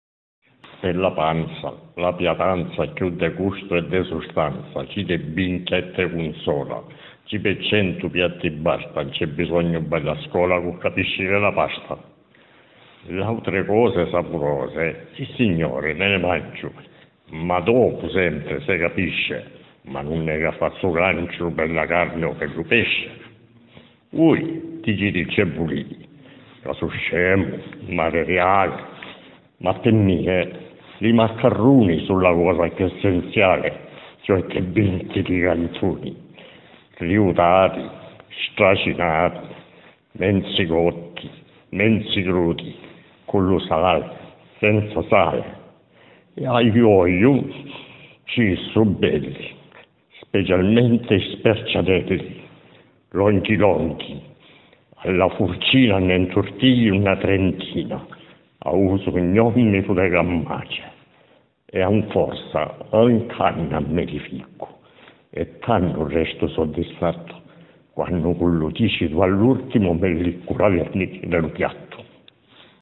Registrazioni vocali dialettali